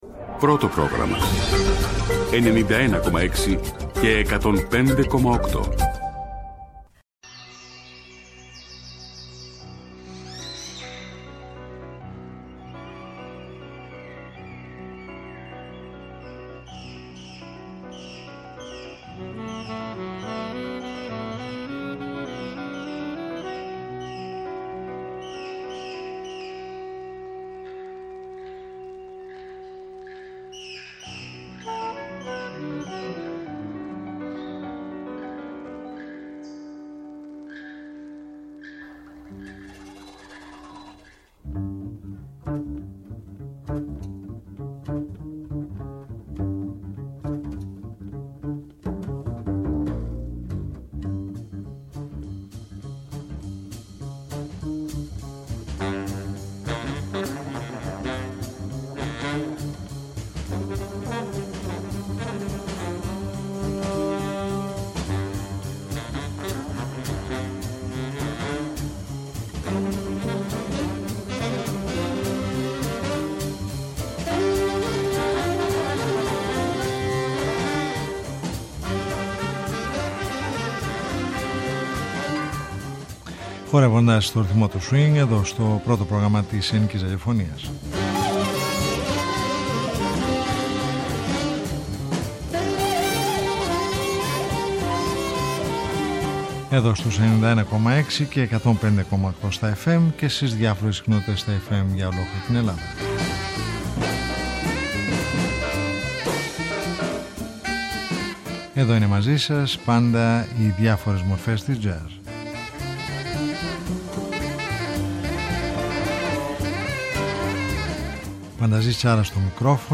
Χορεύοντας στον ρυθμό του Swing : Μια εβδομαδιαία ωριαία συνάντηση με τις διάφορες μορφές της διεθνούς και της ελληνικής jazz σκηνής, κάθε Σάββατο στις 23:00 στο πρώτο Πρόγραμμα.